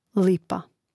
lȉpa lipa